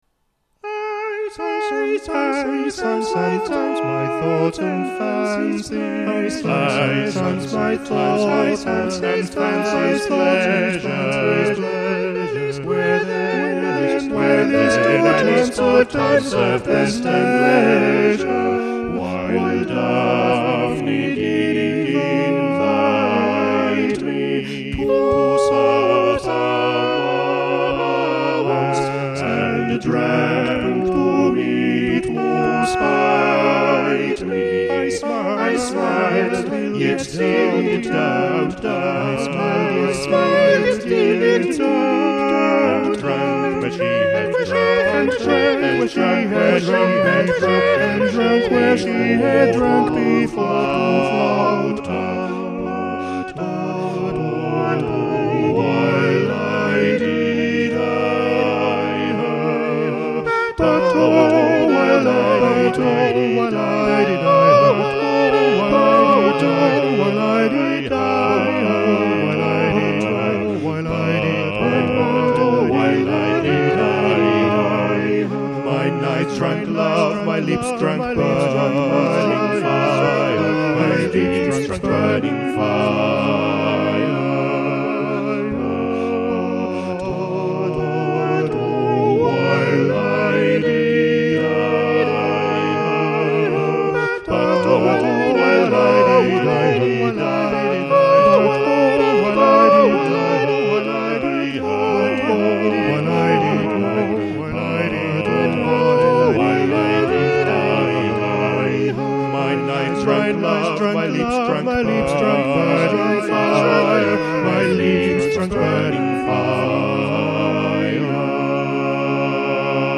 Madrigals for Five Voices